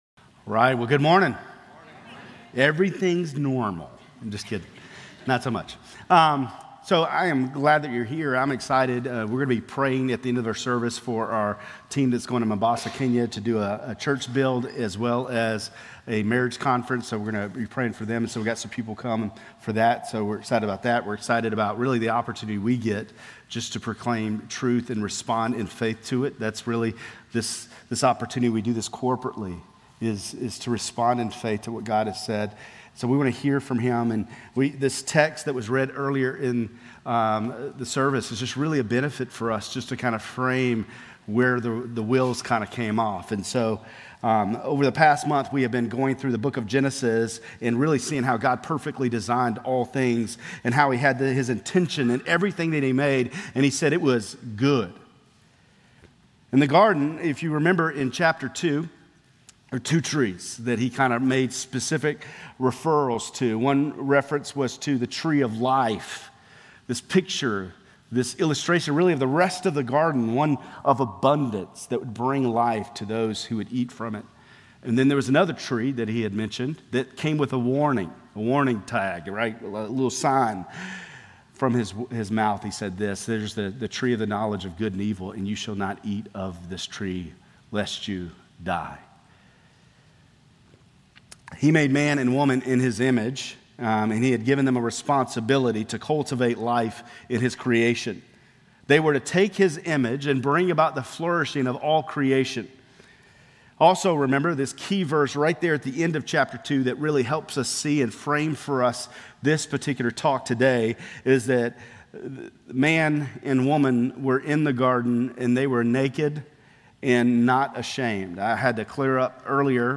Grace Community Church Lindale Campus Sermons Genesis - Sin and the Fall Sep 23 2024 | 00:26:35 1x 00:00 / 00:26:35 Subscribe Share RSS Feed Share Link Embed